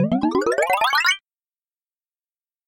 Звуки пополнения счета